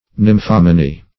Nymphomany \Nym"pho*ma`ny\